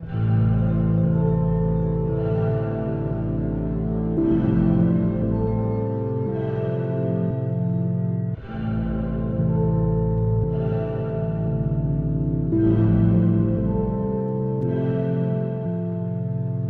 Perfect for Trap, but works well with R&B and Pop too. Dark and deep textures to bring more fill to your songs.